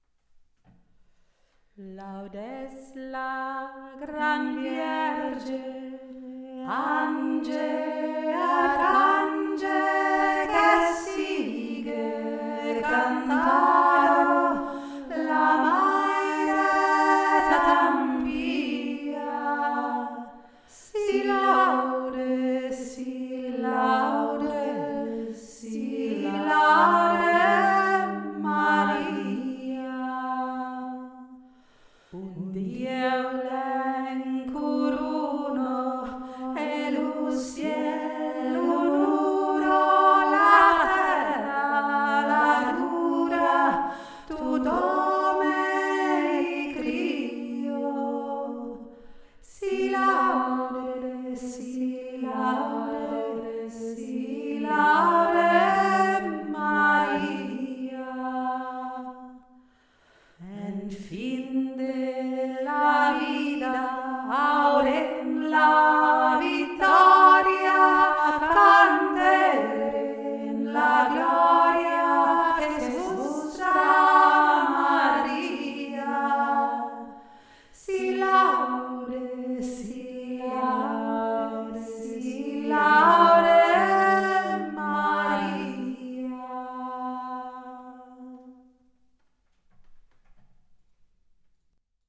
SI LAUDE MARIA - sardisches Marienlied
Si Laude zweistimmig